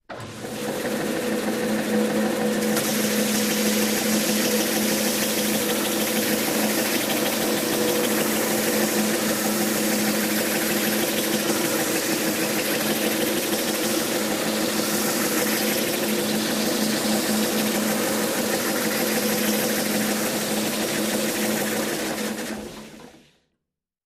SanderMotorizedLar TE045402
Sander, Motorized, Large Table Belt Sander.